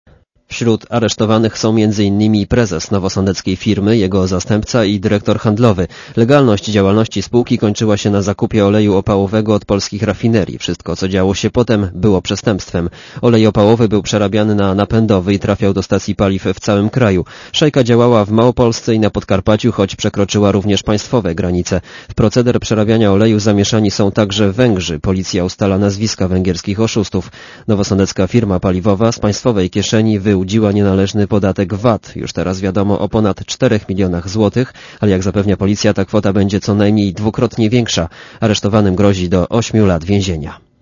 Posłuchaj relacji reportera Radia Zet (160 MB)